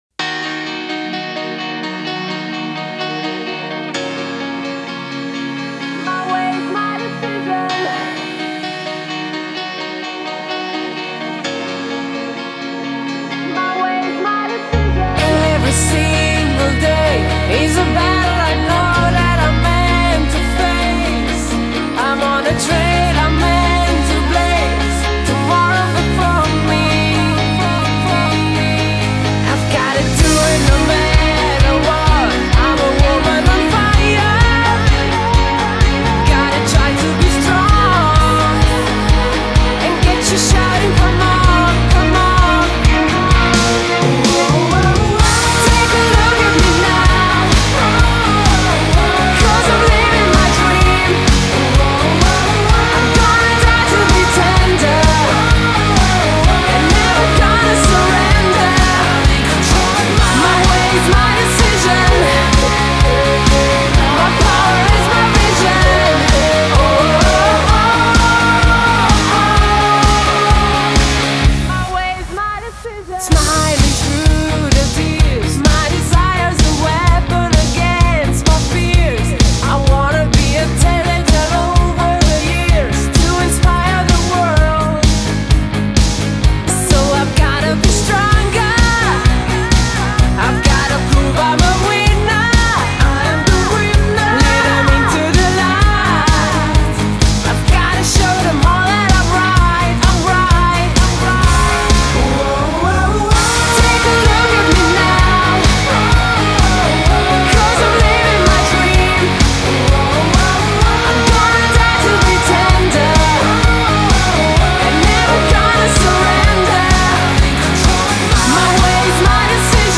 modern mainstream radio friendly up-tempo song